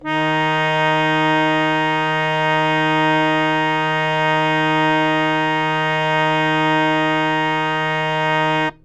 interactive-fretboard / samples / harmonium / Ds3.wav